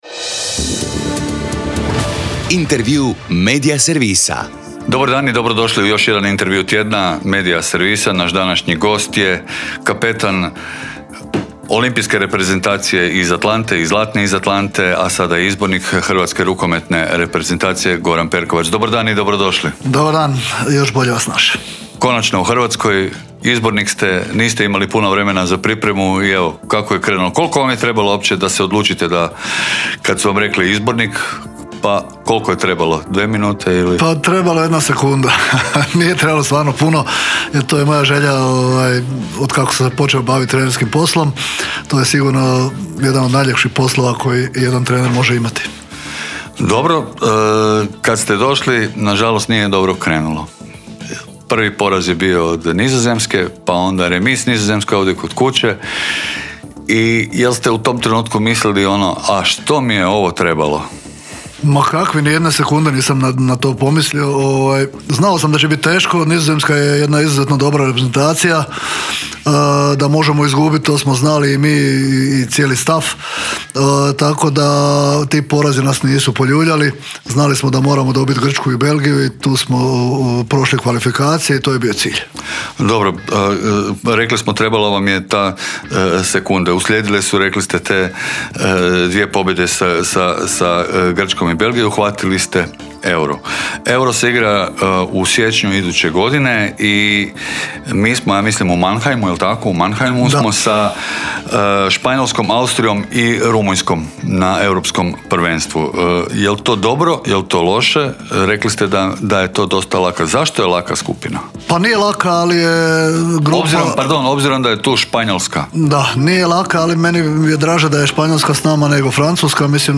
ZAGREB - Gost Intervjua tjedna Media servisa bio je Goran Perkovac, bivši kapetan zlatne olimpijske reprezentacije iz Atlante, a danas izbornik muške...